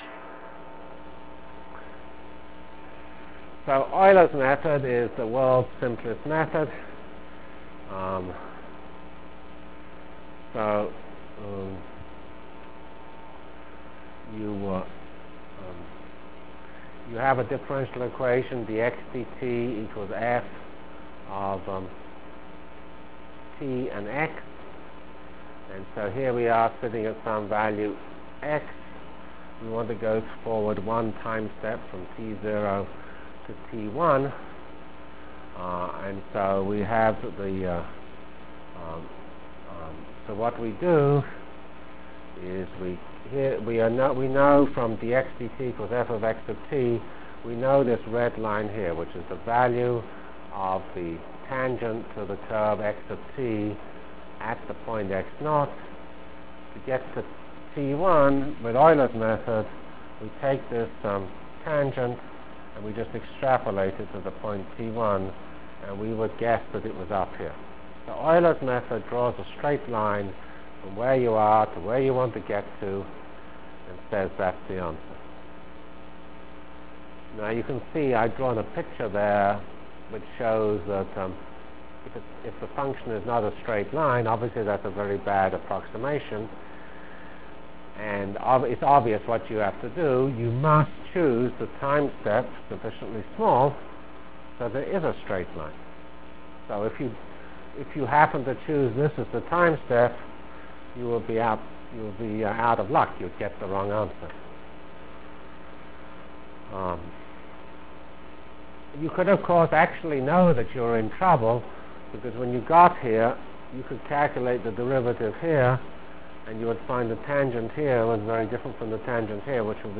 From CPS615-Discussion of Ordinary Differential Equations and Start of Parallel N-Body Algorithm Delivered Lectures of CPS615 Basic Simulation Track for Computational Science